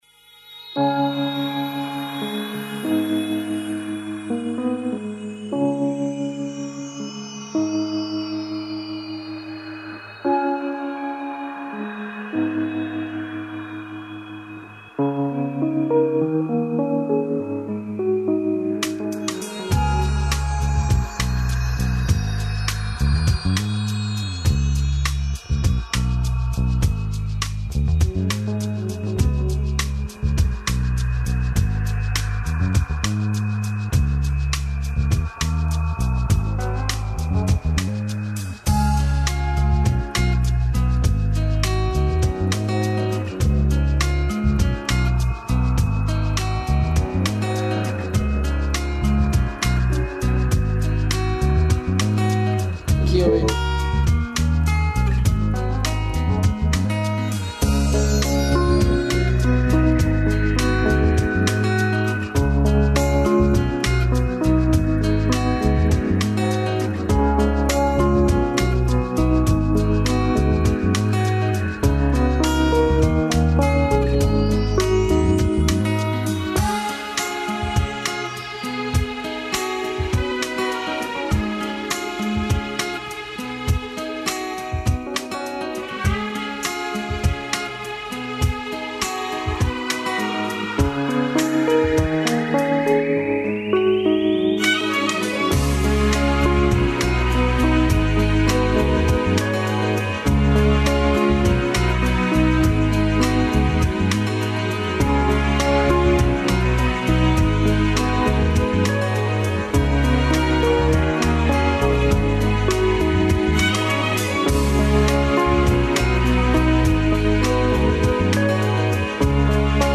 Специјално издање емисије, које можете да пратите и путем Видео стриминга. Наш гост је Никола Чутурило, који ће да одржи акустични сет, као загревање пред велики београдски концерт у Дому омладине.